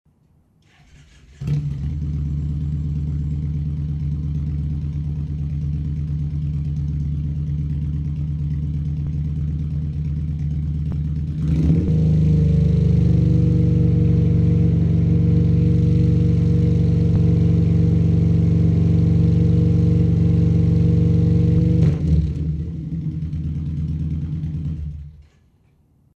Everything was recorded at exactly the same level.
No baffle at all, straight pipe
78 db idle 91 db 3000 rpm